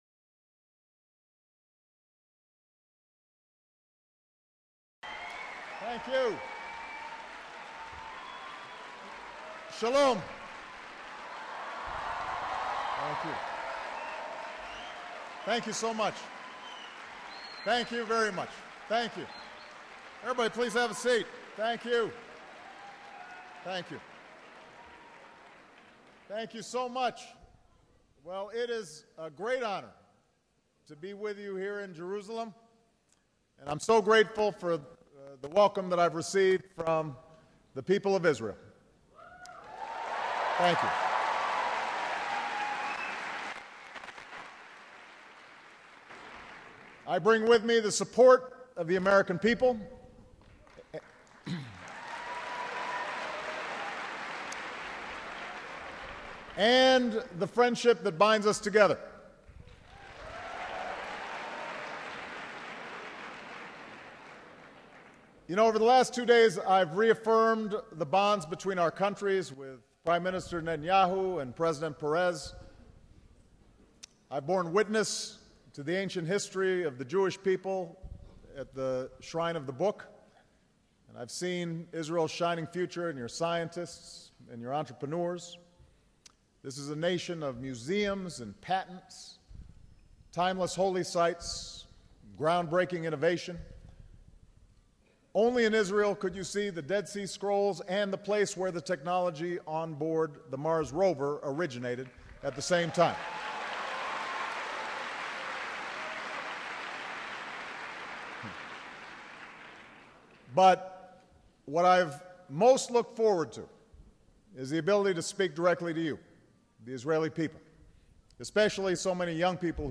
March 21, 2013: Address to the People of Israel